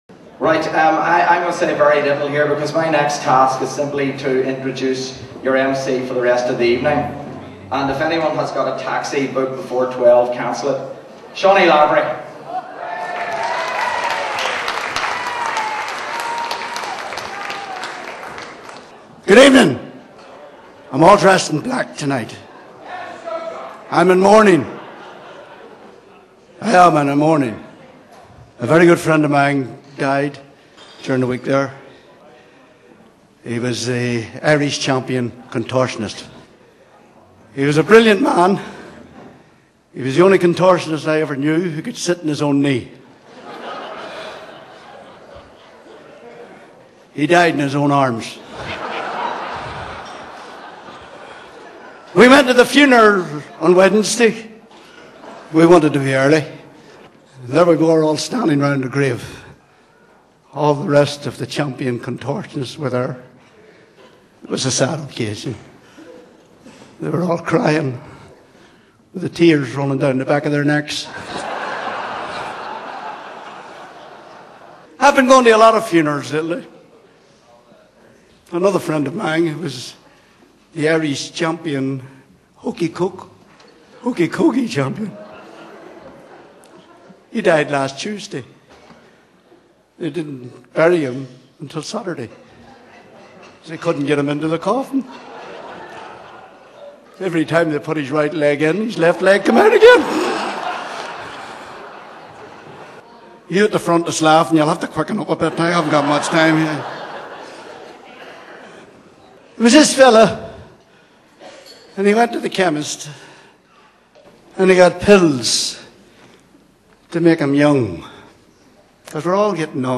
At last, the long-awaited 2006 REUNION CONCERT DVD soundtrack!!
Compere